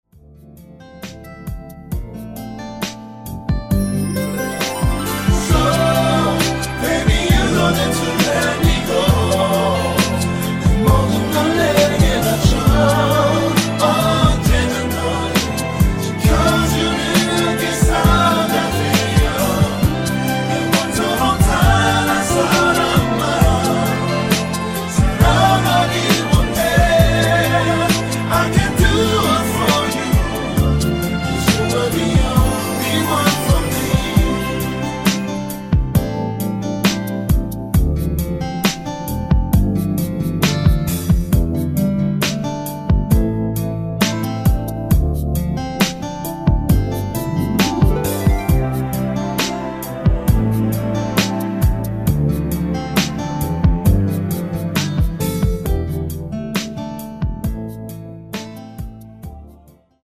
-2)코러스 포함 MR 입니다.
앞부분30초, 뒷부분30초씩 편집해서 올려 드리고 있습니다.
중간에 음이 끈어지고 다시 나오는 이유는
곡명 옆 (-1)은 반음 내림, (+1)은 반음 올림 입니다.